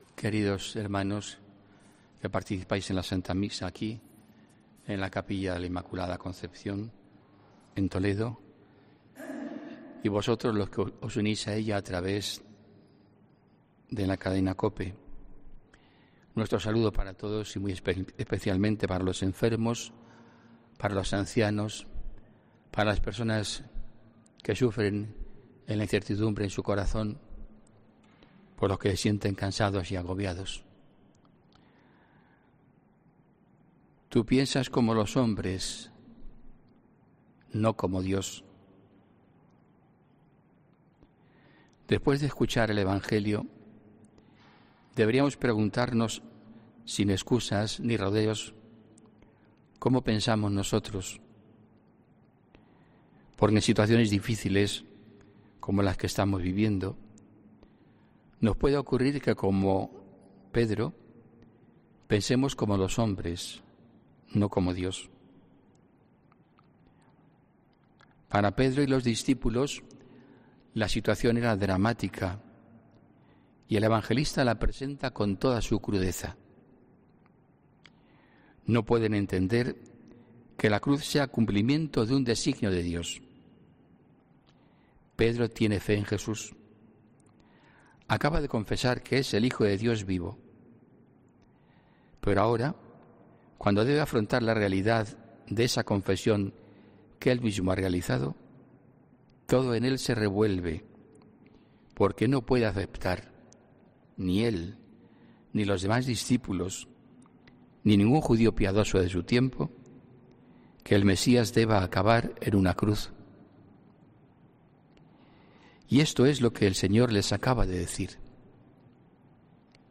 HOMILÍA 30 AGOSTO 2020